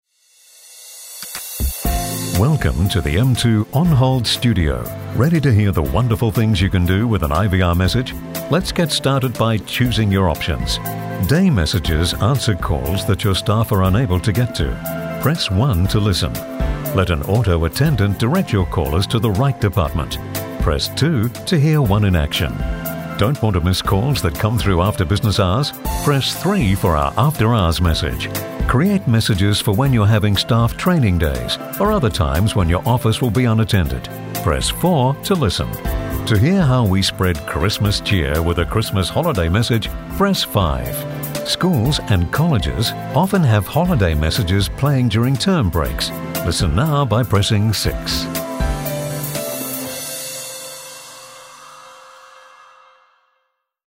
IVR Messages